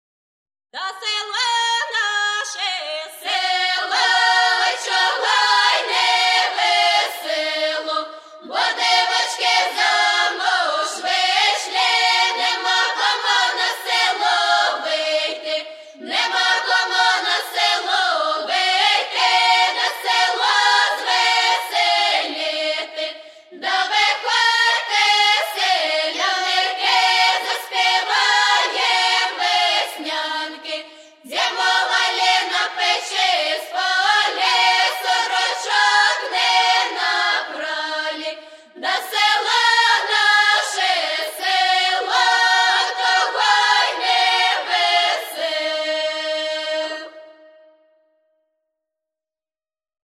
Traditional Music of Rivne Region's Polissya